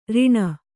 ♪ riṇa